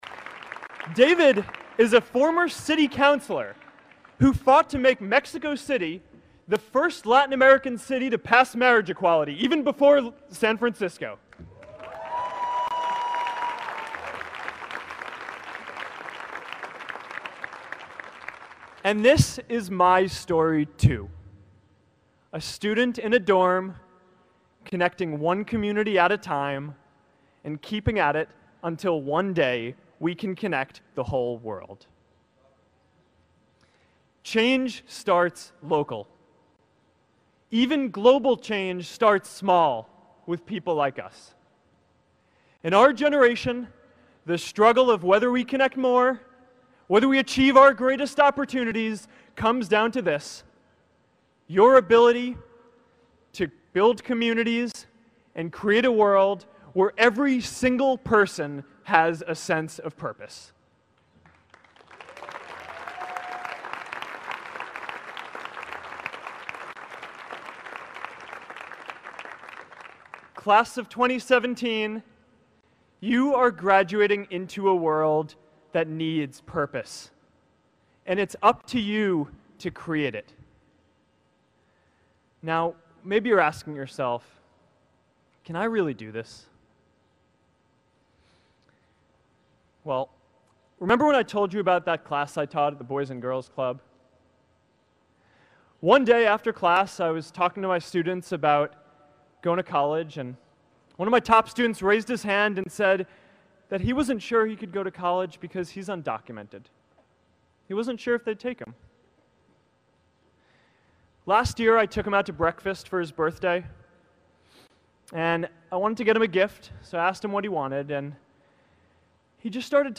公众人物毕业演讲 第470期:扎克伯格2017哈佛毕业演讲(14) 听力文件下载—在线英语听力室